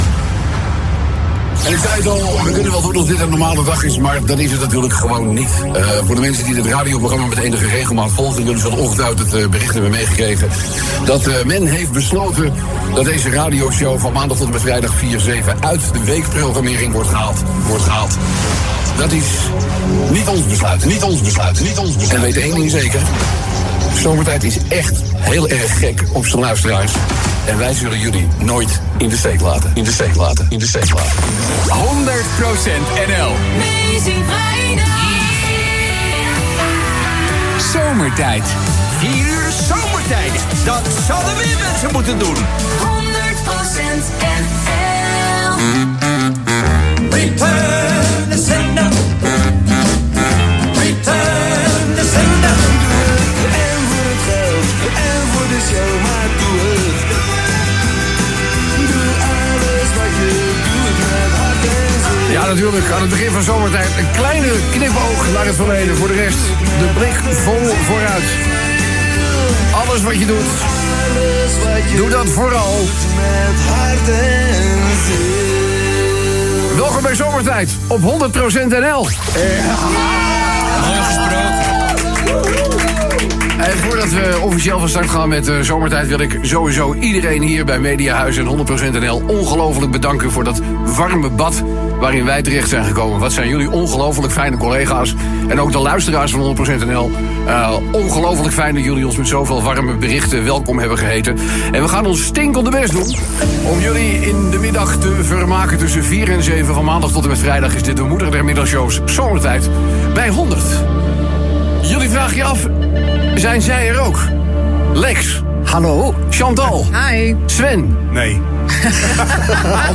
Het programma begon met een kort fragment van het moment dat Van Someren op Radio 10 bekendmaakte dat zijn middagshow zou verdwijnen uit de weekprogrammering. , gevolgd door ‘Return to Sender’ van Elvis.
Dat bad is nog eens extra warm doordat Van Someren zijn eerste show op 100% NL maakt vanuit dezelfde studio als hij zijn eerste programma in 2015 op Radio 10 maakte.